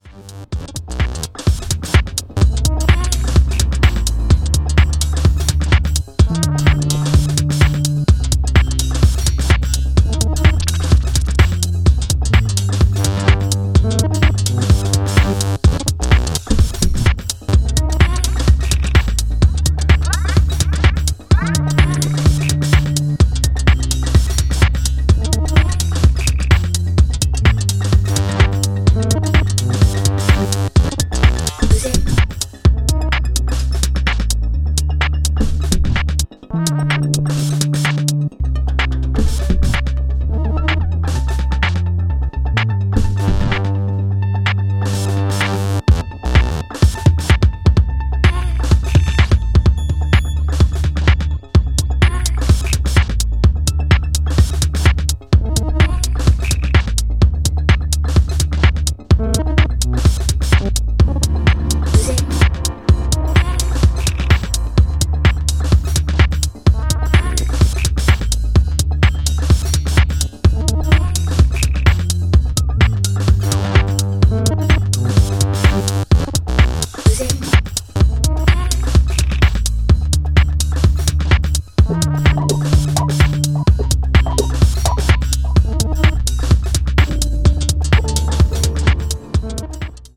remade beat to make it as dreamy and trippy as possible
who made groovy and a bit crazy remix for the afterhours.